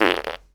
pgs/Assets/Audio/Comedy_Cartoon/fart_squirt_04.wav at 7452e70b8c5ad2f7daae623e1a952eb18c9caab4
fart_squirt_04.wav